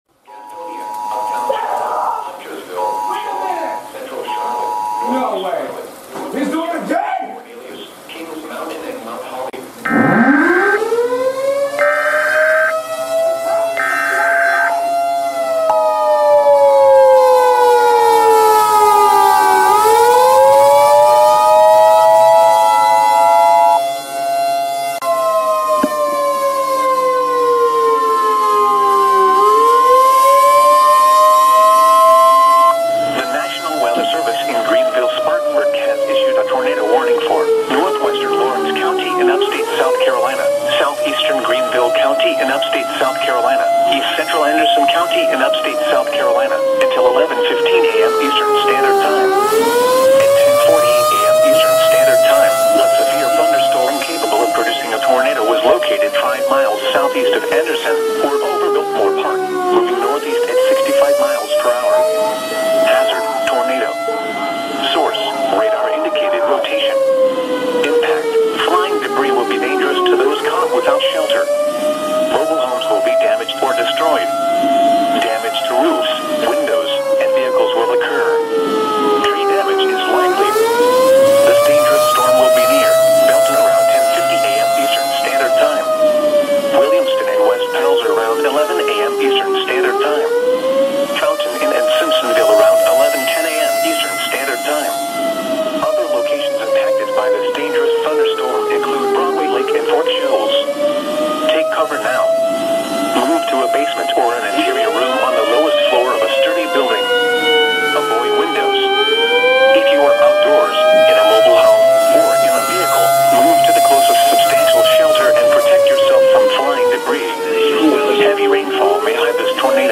My Thunderbolt 1000 Emergency Activations